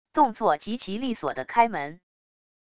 动作极其利索地开门wav音频